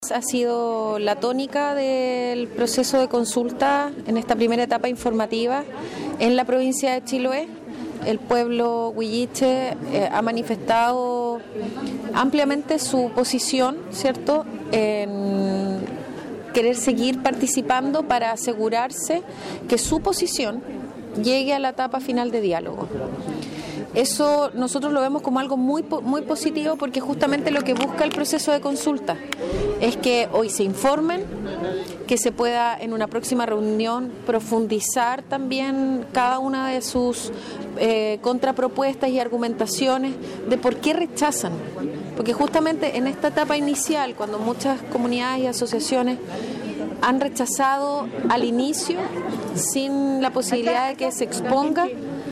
Respecto de esta fase de la consulta, también se expresó confiada en el buen término del proceso, la seremi de Desarrollo Social, Soraya Said.